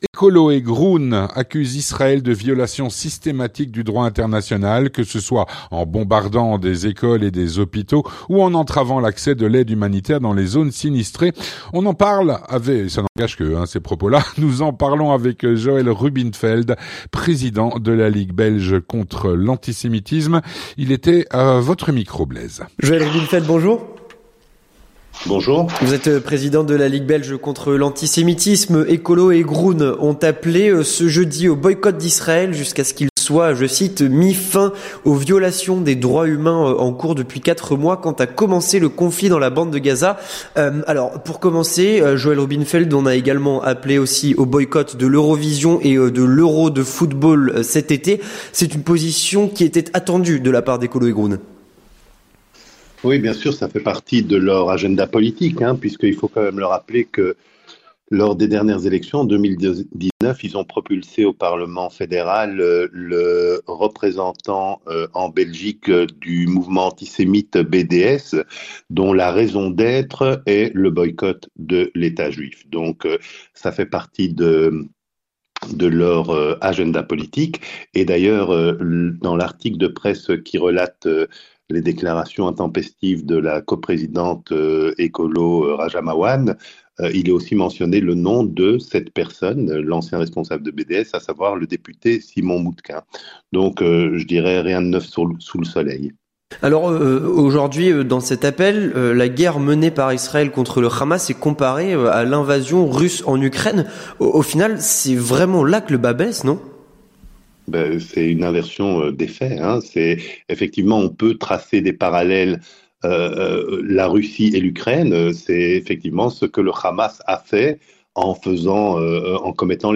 L'entretien du 18H - Ecolo et Groen accusent Israël de violations systématiques du droit international.